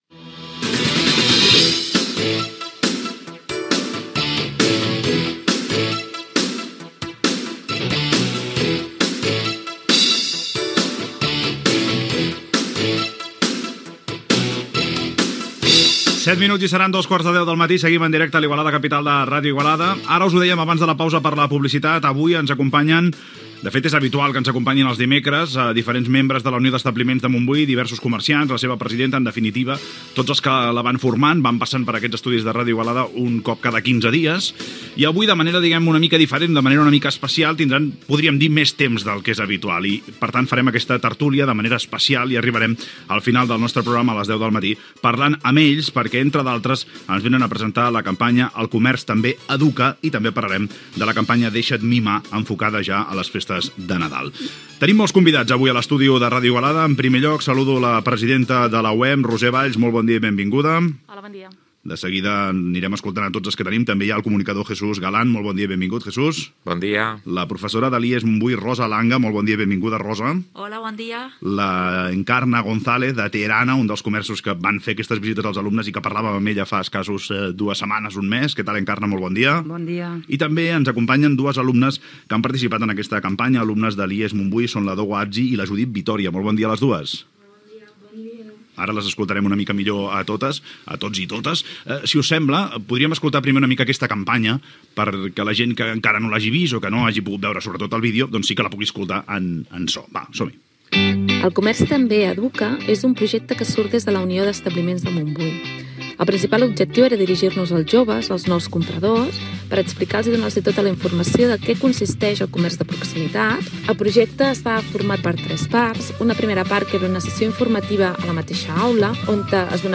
Hora, identificació del programa, espai dedicat al comerç local i la campanya "El comerç també educa", de la Unió d'Establiments de Montbui. Presentació dels invitats i objectius de la campanya.
Info-entreteniment